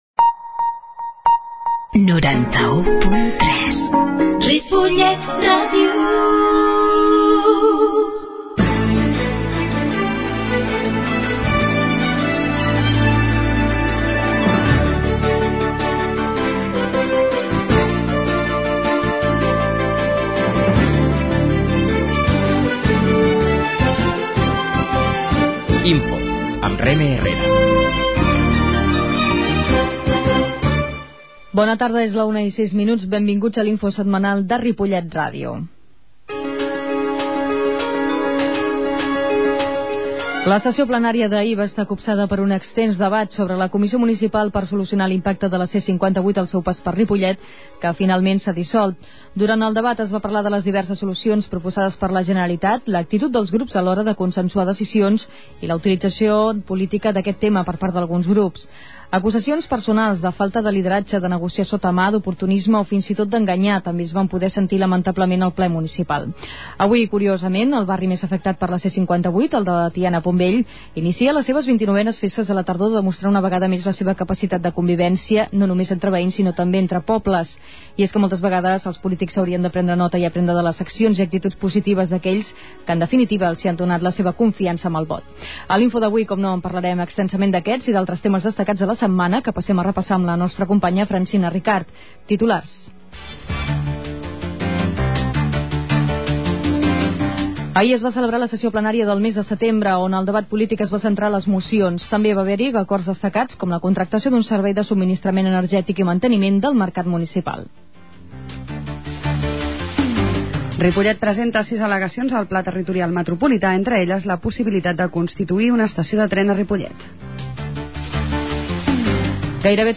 Comunicació L'INFO de la setmana: 25 de setembre de 2009 -Comunicació- 24/09/2009 Aquesta setmana ens han acompanyat a l'INFO de Ripollet R�dio alguns dels membres de la Comissi� de les Festes de la Tardor del barri del Pont Vell, que enguany arriben a la seva 29a edici�. A m�s, hem parlat sobre la recerca de feina amb dues entrevistes amb l'oficina local del Servei d'Ocupaci� de Catalunya (SOC) i el Patronat Municipal de Comunicaci�.
La qualitat de so ha estat redu�da per tal d'agilitzar la seva desc�rrega.